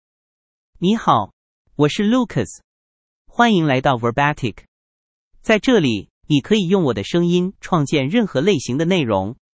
Lucas — Male Chinese (Mandarin, Simplified) AI Voice | TTS, Voice Cloning & Video | Verbatik AI
Lucas is a male AI voice for Chinese (Mandarin, Simplified).
Voice sample
Listen to Lucas's male Chinese voice.
Male